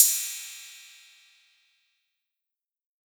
Southside Open Hatz (4).wav